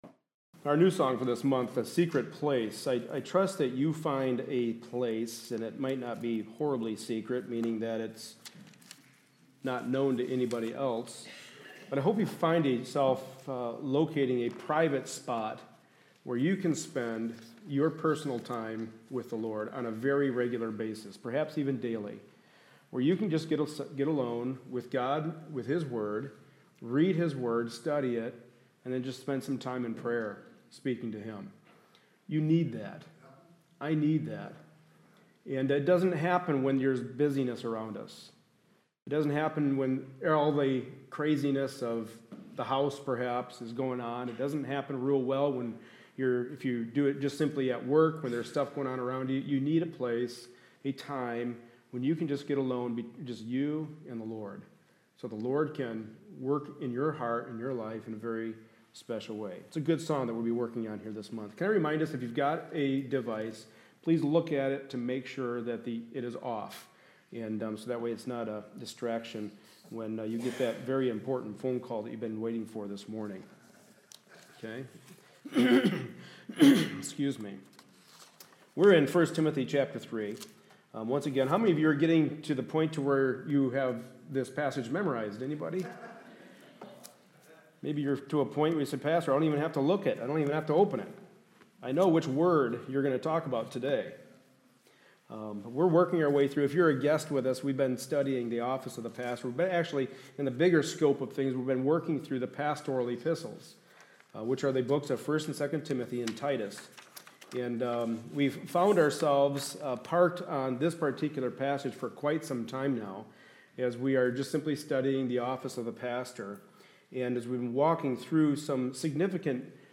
The Book of 1st Timothy Service Type: Sunday Morning Service A study in the pastoral epistles.